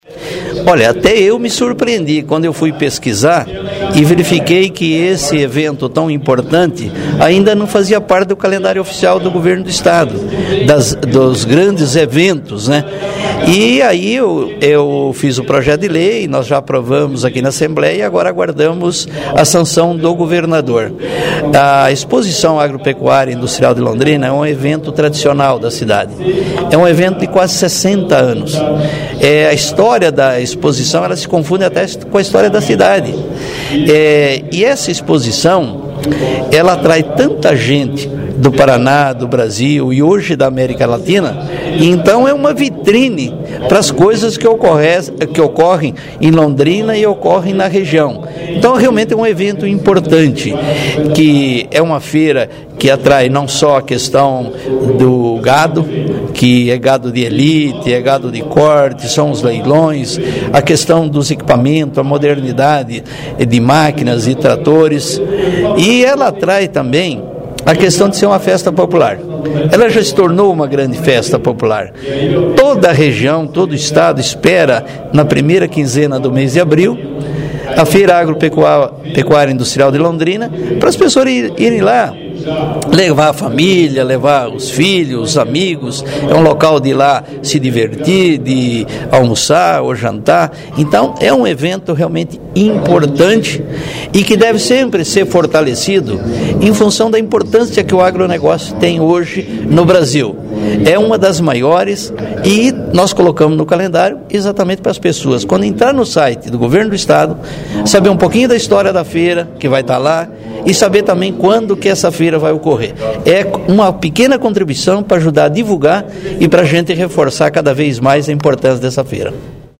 O deputado Tercílio Turini, do PPS, fala sobre a aprovação do projeto que pode inserir a ExpoLondrina no calendário oficial de eventos do Estado.//Turini explica que, apesar de já ter 54 anos de atividade e ser uma das maiores do país, a feira ainda não tem este destaque no calendário de atividades...